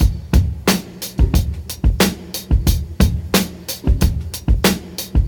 • 90 Bpm Drum Loop G Key.wav
Free drum groove - kick tuned to the G note.
90-bpm-drum-loop-g-key-26z.wav